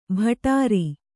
♪ bhatāri